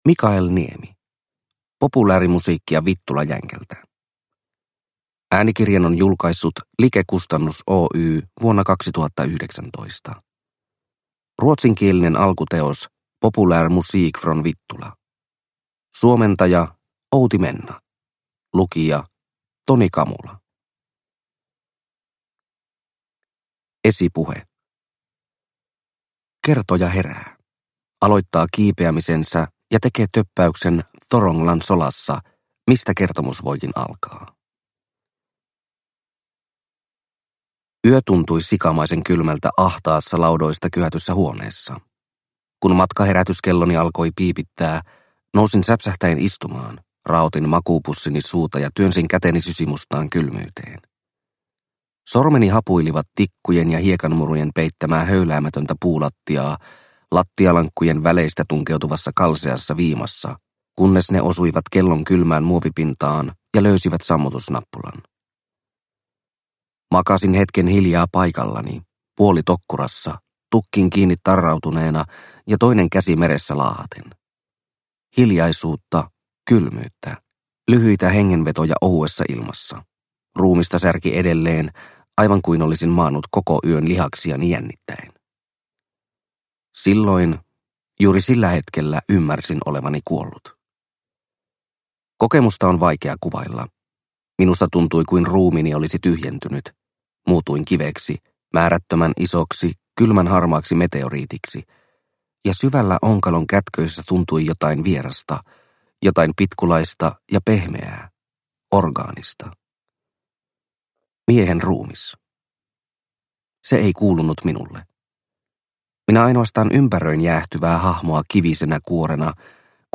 Populäärimusiikkia Vittulajänkältä – Ljudbok – Laddas ner